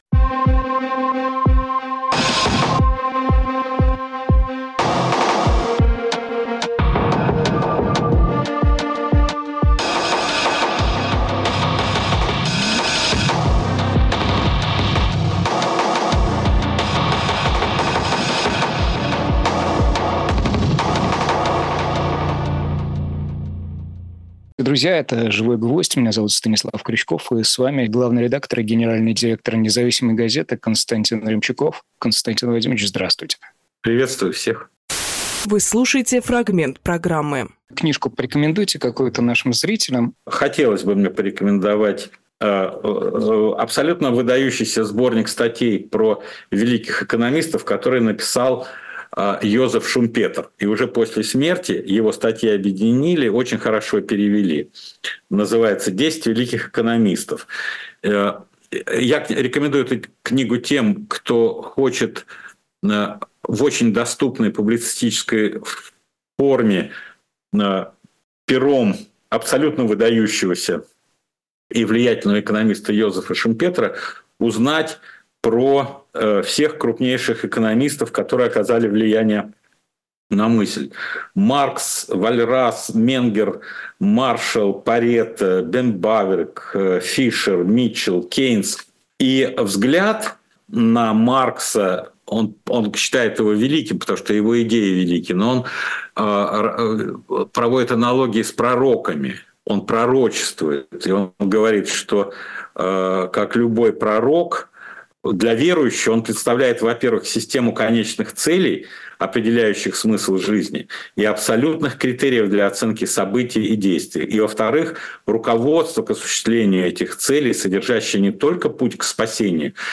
Фрагмент эфира от 17.06.24